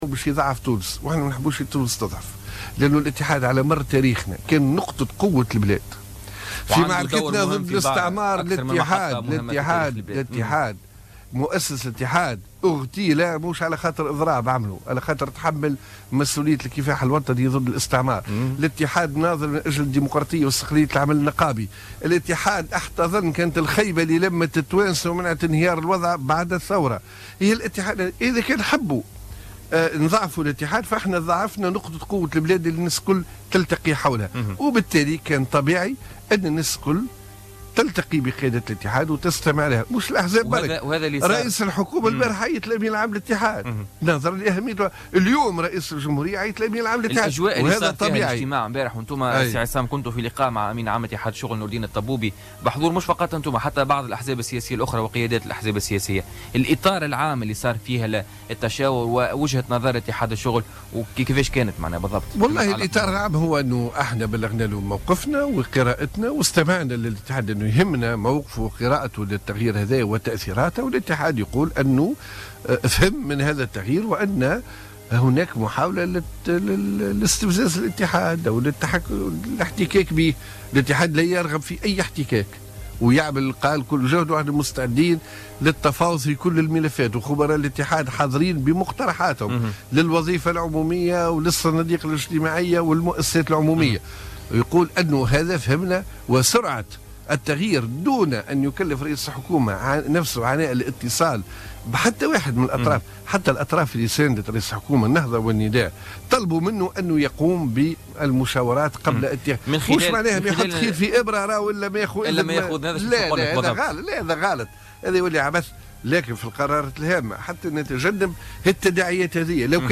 أكد الأمين العام للحزب الجمهوري عصام الشابي ضيف بولتيكا اليوم الجمعة أن اتحاد الشغل كان على مر التاريخ نقطة قوة لتونس وأنه ناضل من أجل الإستقلال وكان الخيمة التي جمعت كل التونسيين بعد الثورة ومنعت انهيار الوضع على حد قوله.